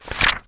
This page contains a sound effect asset in .wav format to download.
Page Flip #1
page-flip-1.wav